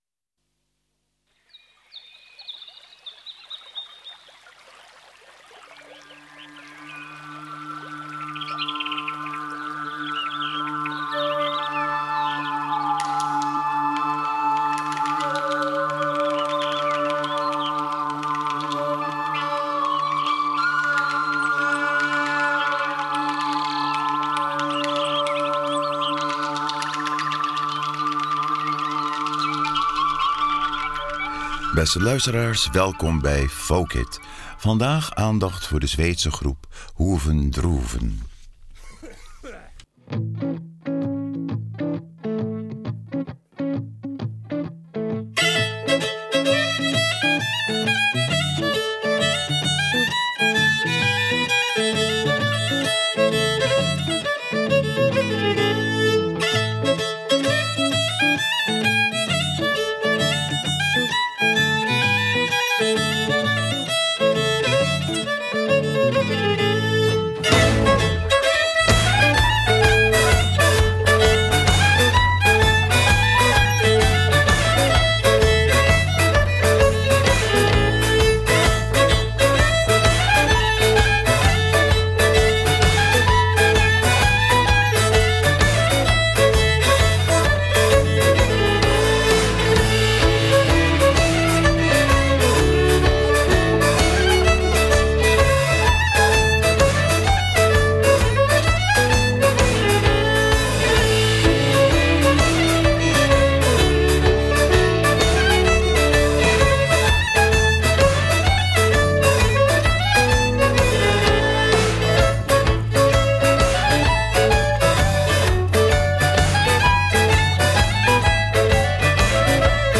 viool
gitaar
diverse koperblaasinstrumenten en accordion
basgitaar
saxofoon
slagwerk
voornamelijk instrumentaal